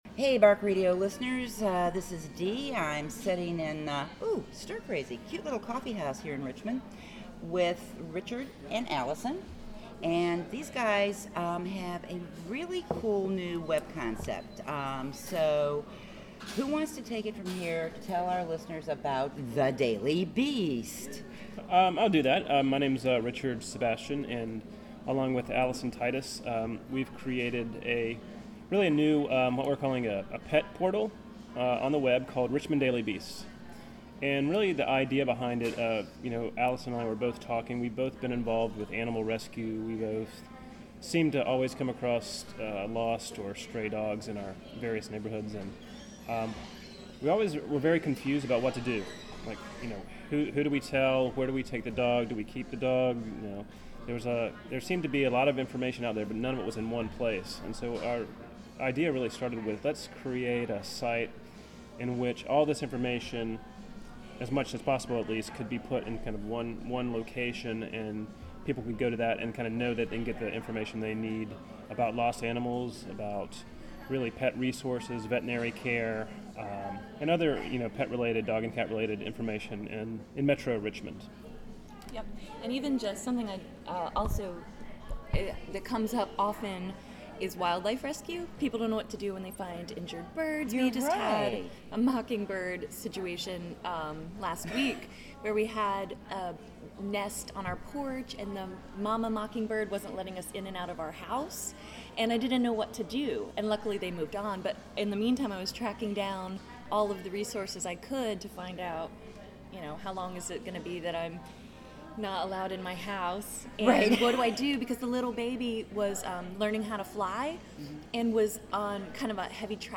This episode of Bark Radio was recorded at Stir Crazy, a great local coffee house.